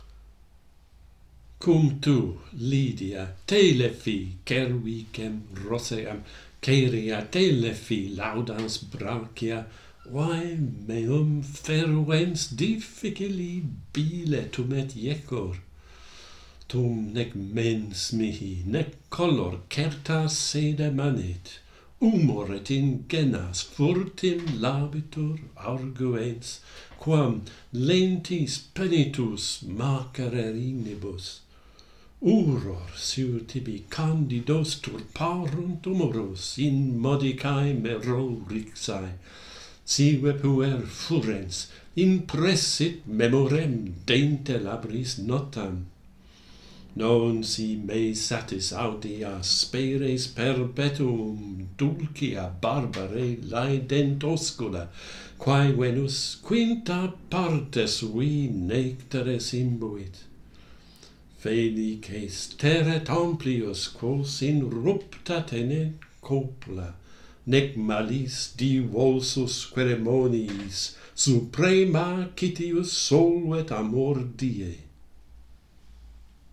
Jealousy - Pantheon Poets | Latin Poetry Recited and Translated